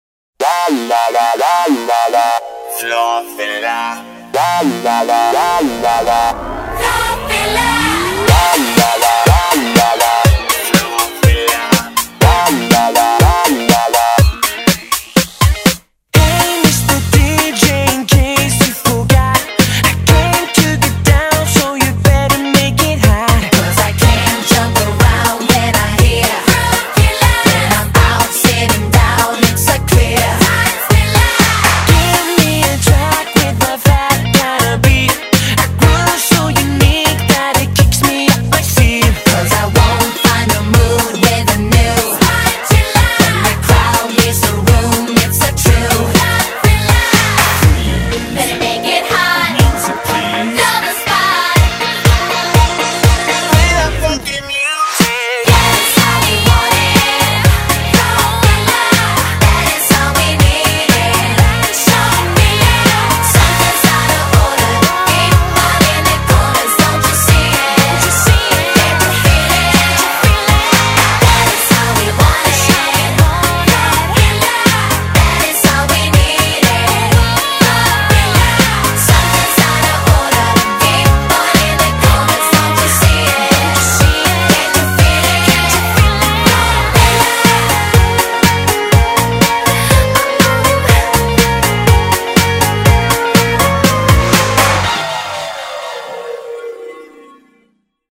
BPM122--1
Audio QualityPerfect (High Quality)